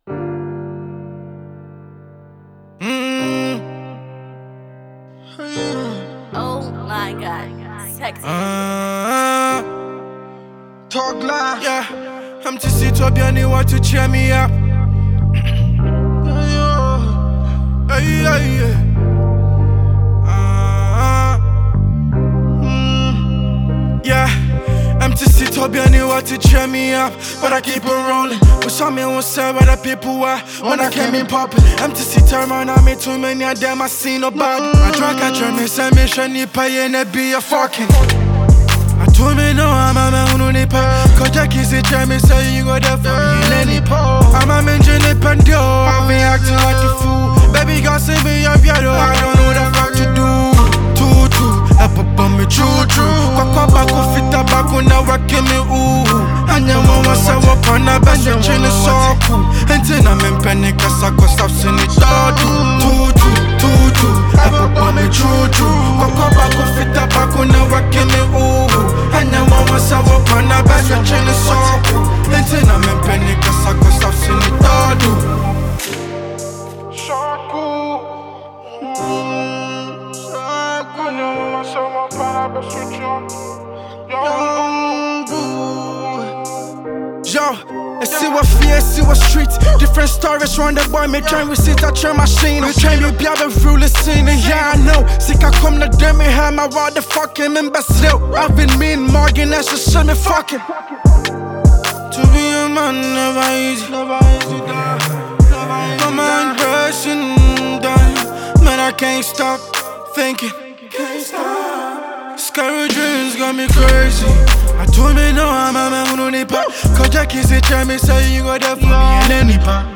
Afro rapper